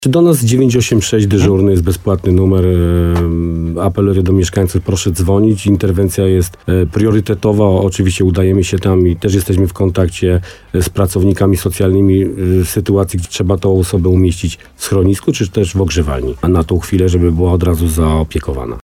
Komendant Wojciech Tyrkiel również apeluje o zaangażowanie, bo nasza postawa może uratować czyjeś zdrowie i życie.